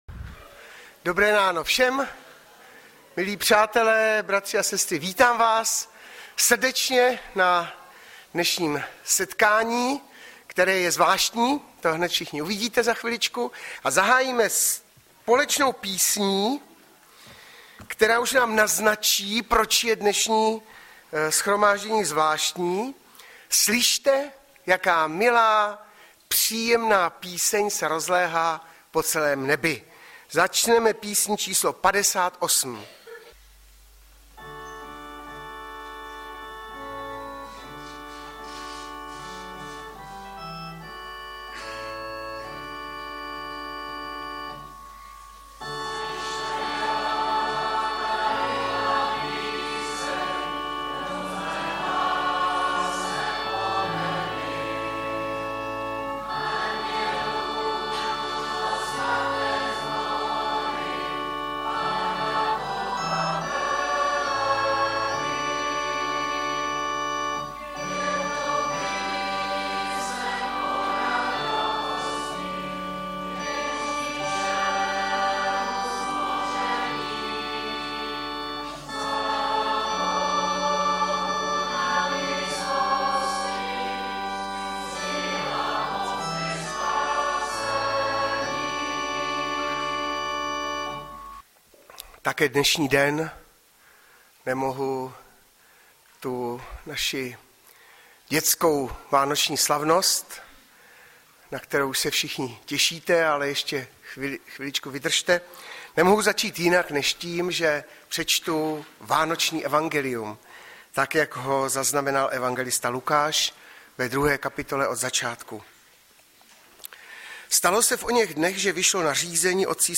20.12.2015 - DĚTSKÁ VÁNOČNÍ SLAVNOST